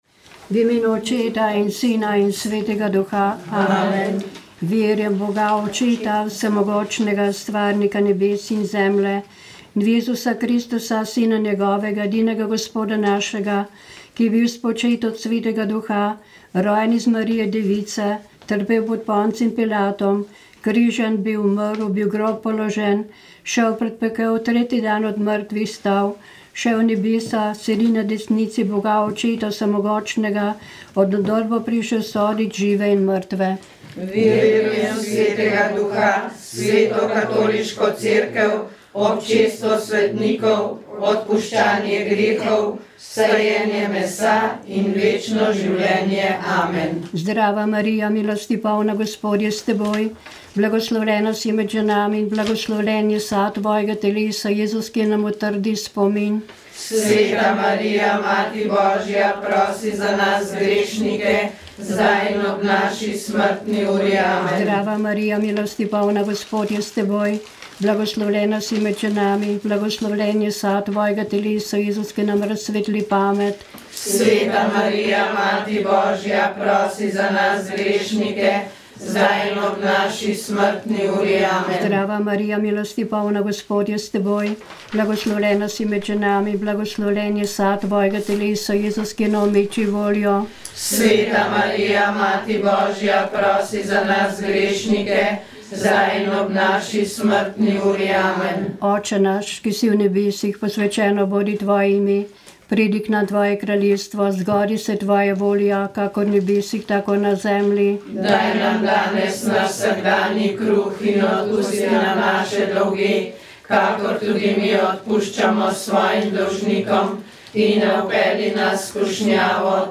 Rožni venec
Molili so člani Karitas iz župnij Preska in Smlednik.